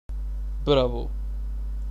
Descarga de Sonidos mp3 Gratis: bravo.
bravo-sound.mp3